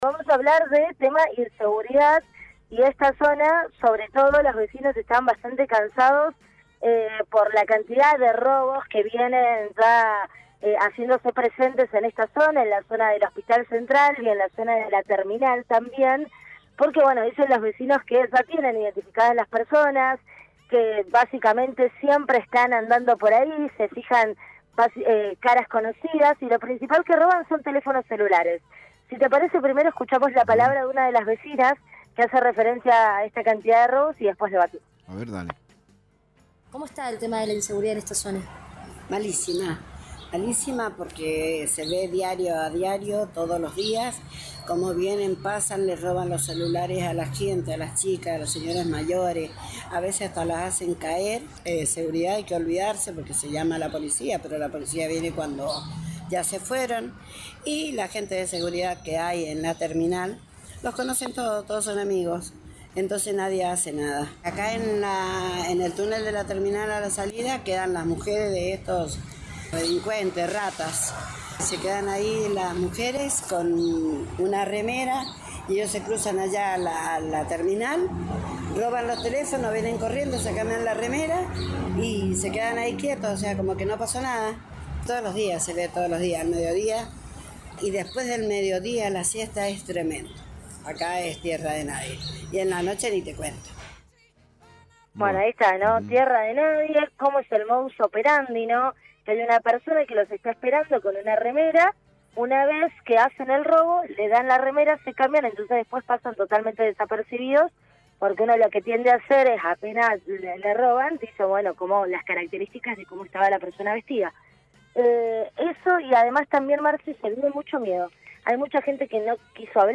LVDiez - Radio de Cuyo - Móvil de LVDiez- testimonio inseguridad en Guaymallén